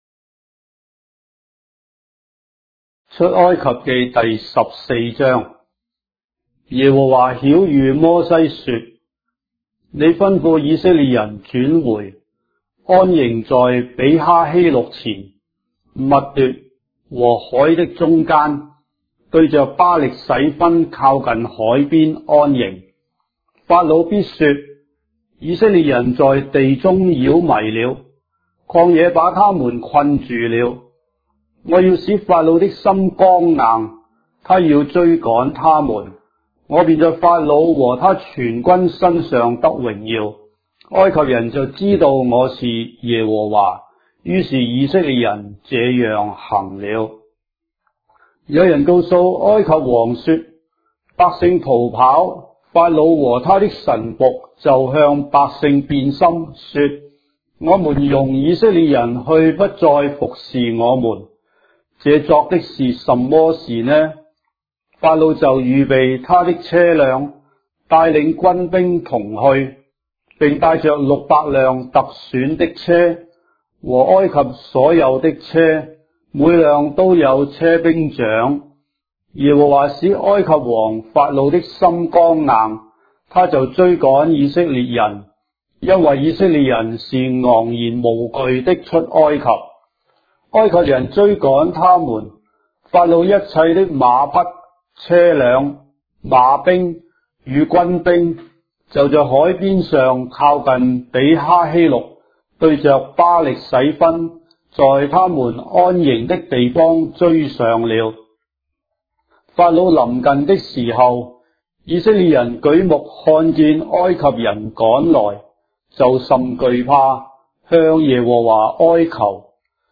章的聖經在中國的語言，音頻旁白- Exodus, chapter 14 of the Holy Bible in Traditional Chinese